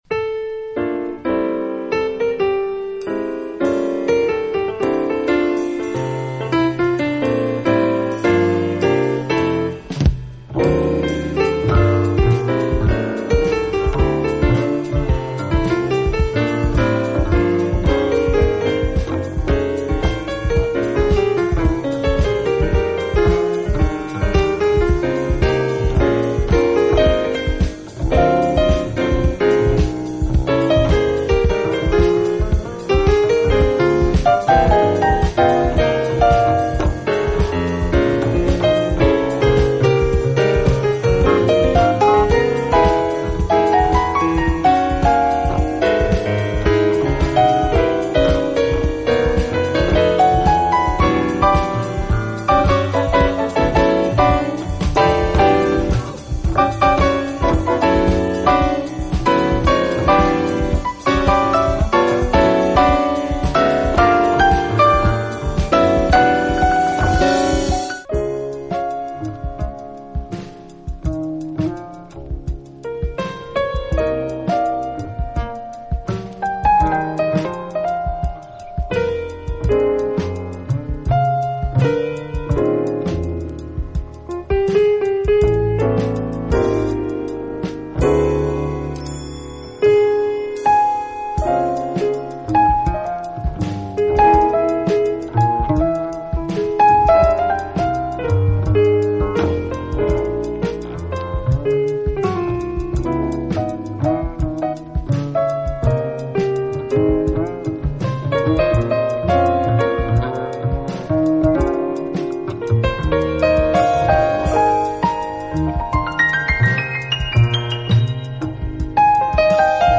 Rare Armenian jazz trio